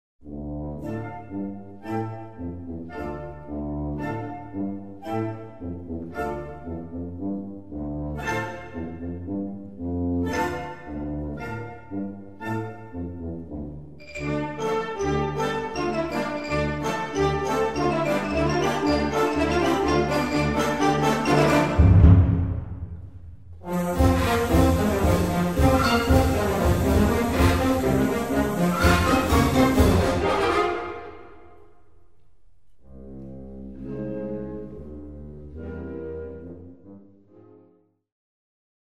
Gattung: Russische Tanzsuite
Besetzung: Blasorchester